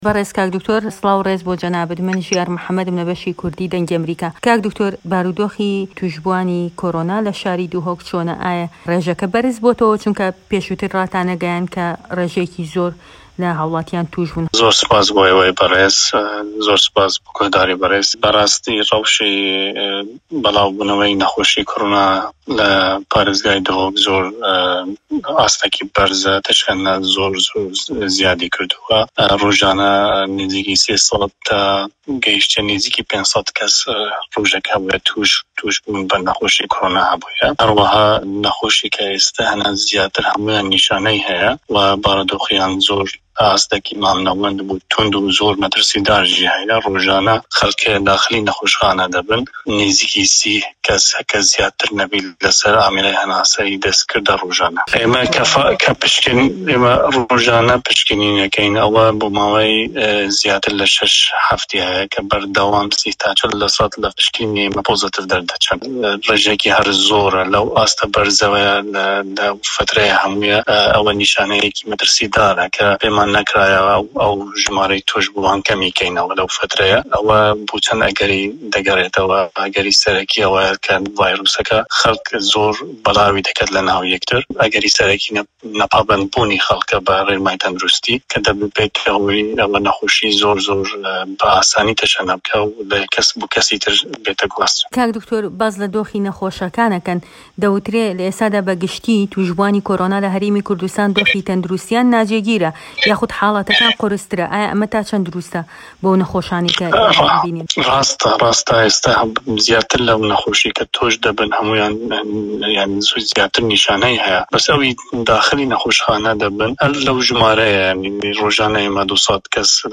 ده‌قی وتووێژه‌كه‌ی